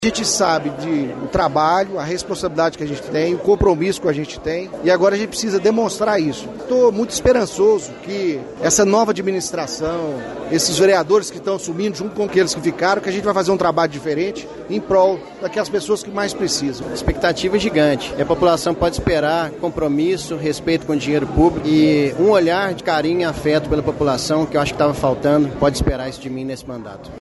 Em conversa com o Jornal da Manhã, os vereadores se mostraram animados com o começo dos trabalhos.